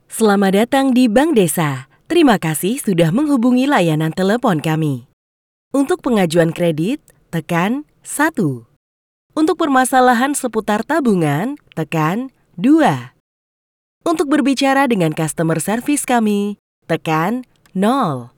Kommerziell, Tief, Erwachsene, Warm, Corporate
Telefonie